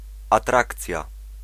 Ääntäminen
IPA : /əˈtɹækʃən/